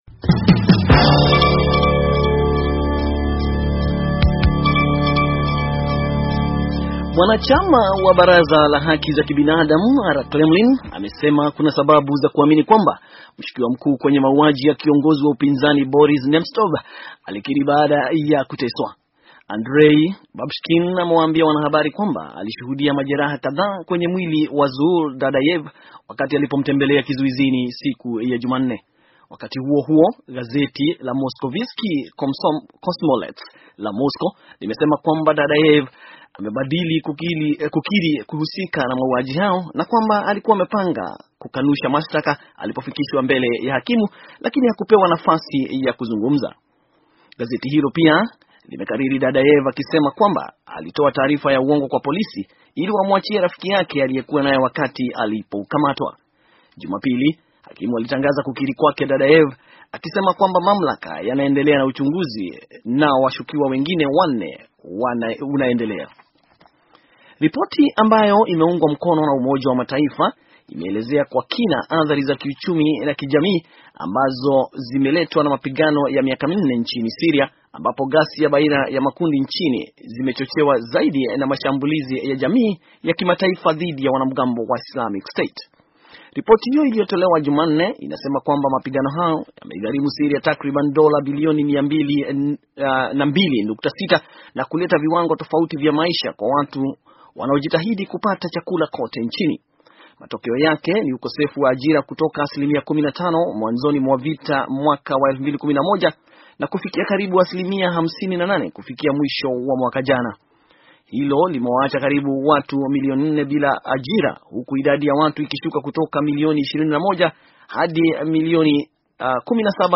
Taarifa ya habari - 5:30